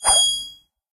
bp_snout_coin_fly_01.ogg